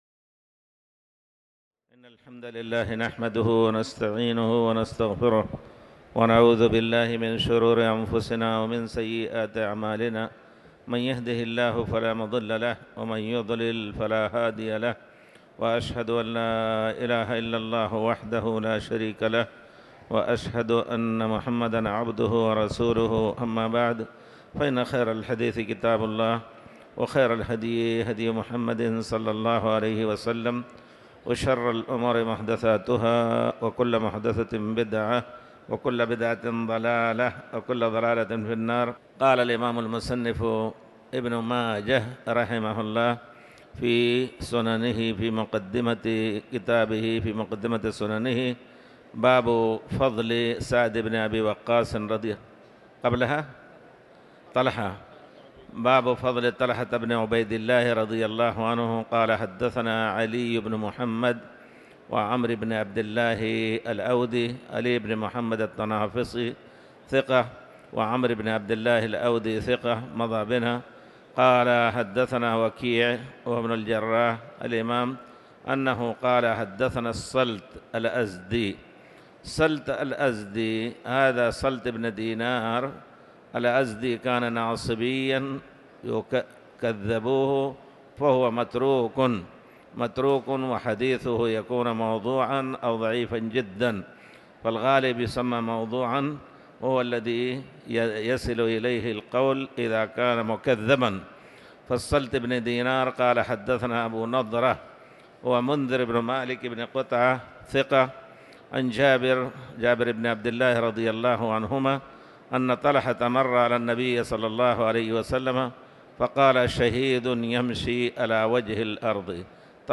تاريخ النشر ٨ ذو القعدة ١٤٤٠ هـ المكان: المسجد الحرام الشيخ